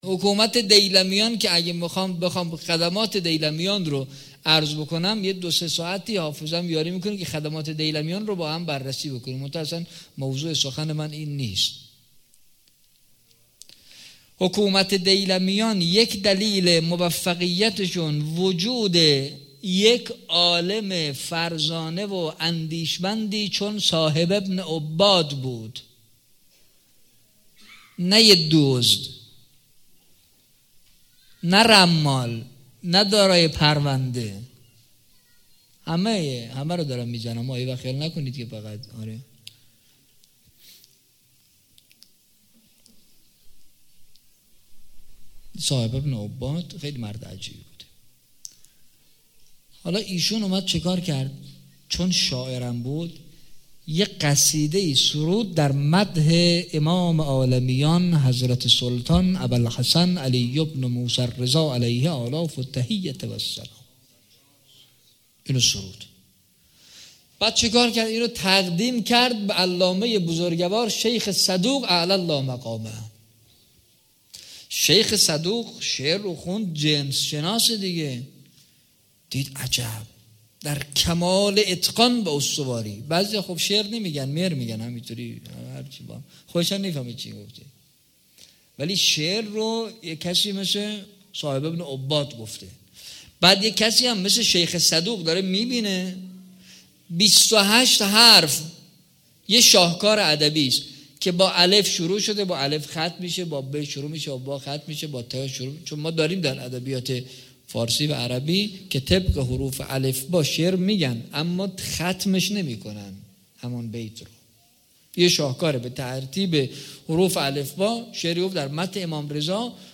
وفات حضرت زينب (س) 96 - انصار المهدی - سخنرانی